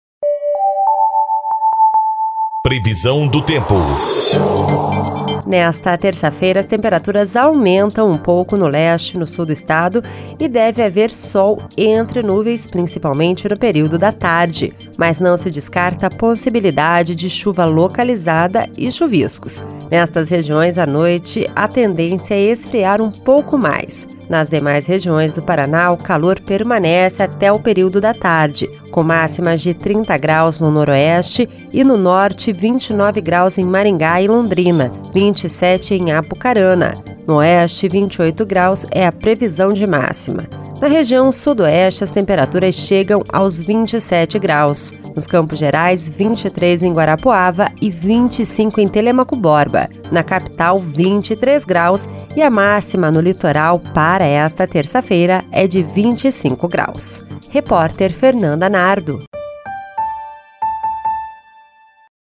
Previsão do Tempo (22/03)